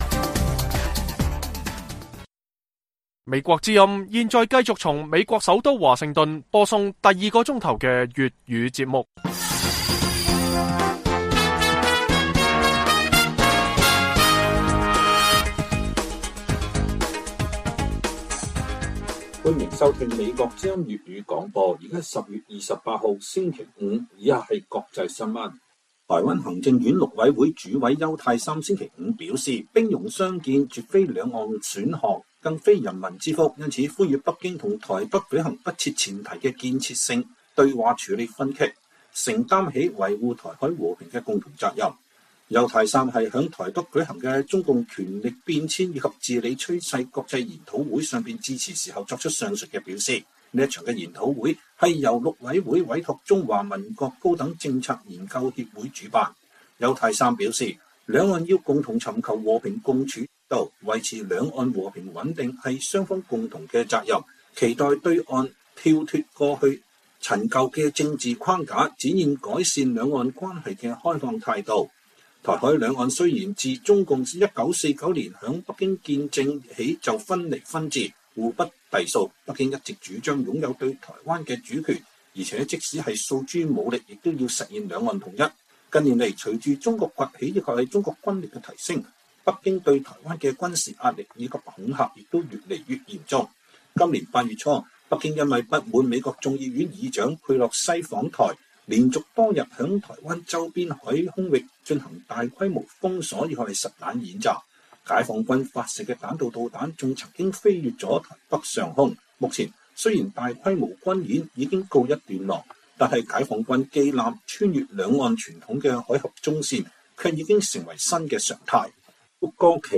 粵語新聞 晚上10-11點 : 專家解讀：中國正在為與西方對抗做準備